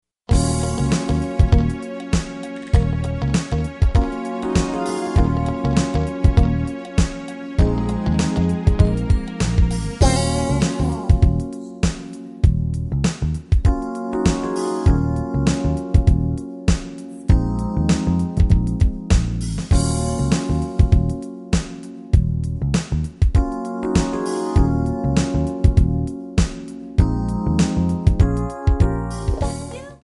Em
MPEG 1 Layer 3 (Stereo)
Backing track Karaoke
Pop, 1990s